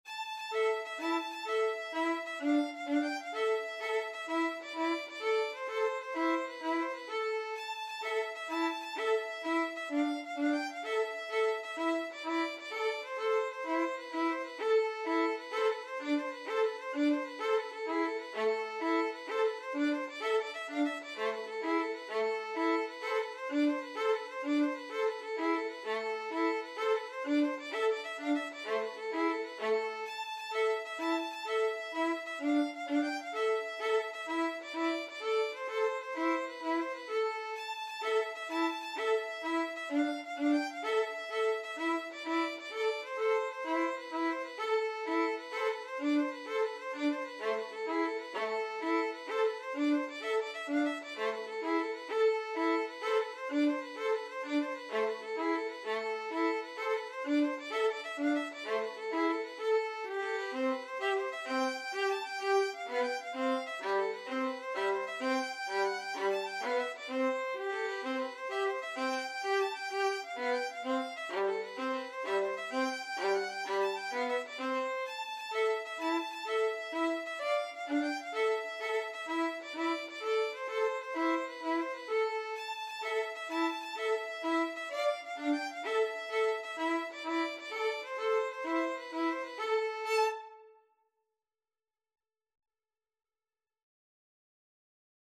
Free Sheet music for Violin Duet
A traditional Neapolitan Italian Tarantella associated with the Southern Italian town of Naples.
A minor (Sounding Pitch) (View more A minor Music for Violin Duet )
6/8 (View more 6/8 Music)